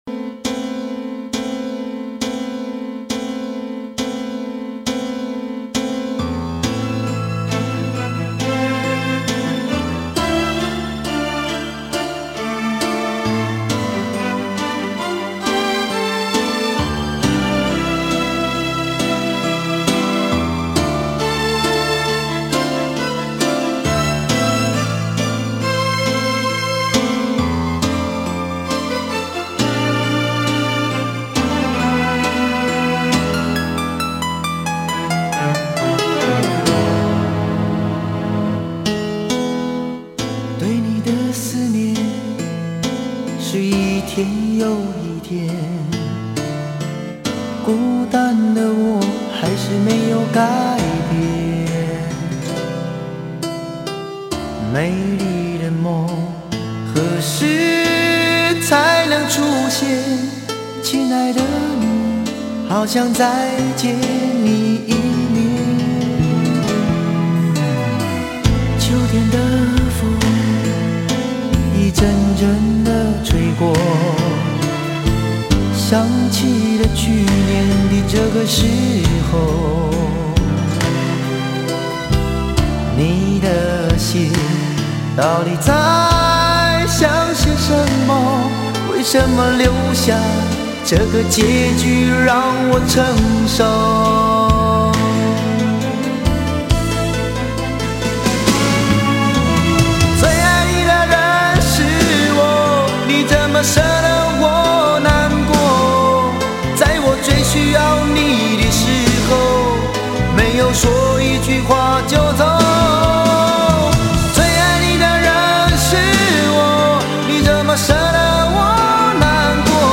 翻唱得很有特点的歌曲